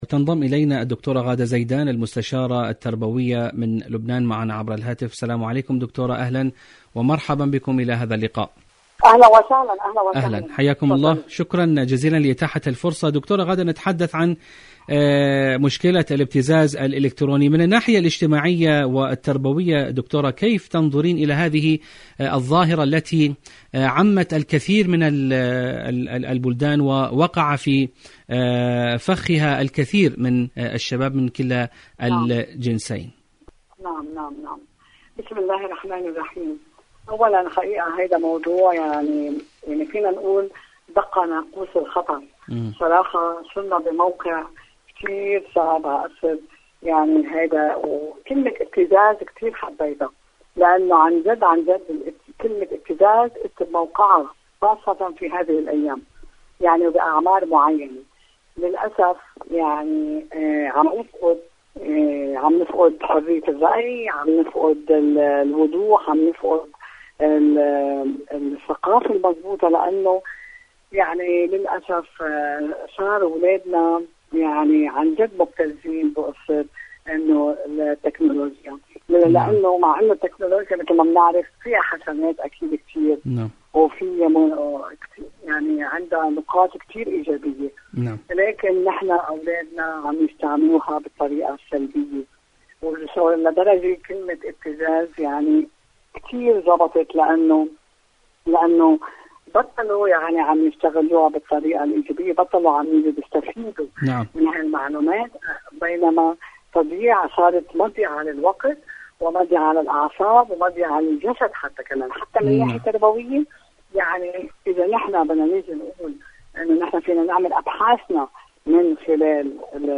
مقابلات برامج إذاعة طهران العربية برنامج دنيا الشباب الشباب مقابلات إذاعية الابتزاز مشكلة الابتزاز الإلكتروني الابتزاز الإلكتروني شاركوا هذا الخبر مع أصدقائكم ذات صلة دور العلاج الطبيعي بعد العمليات الجراحية..